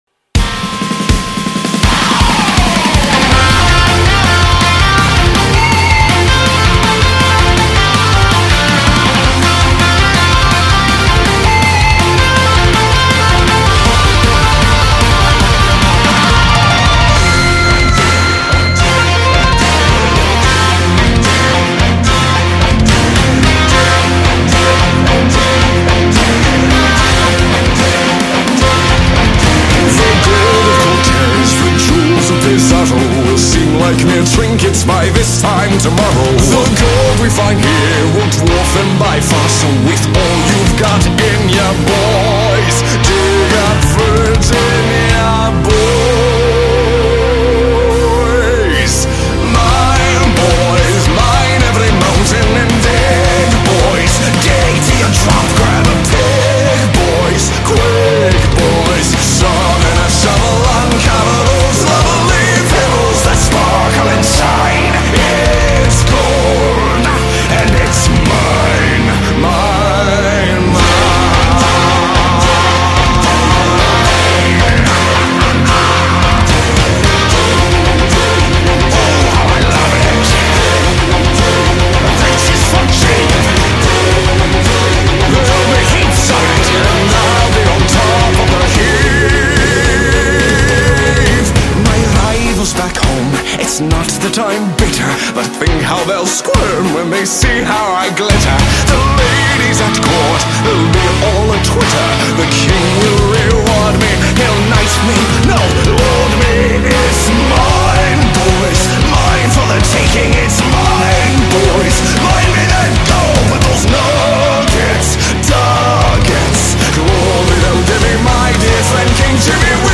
adpatación a heavy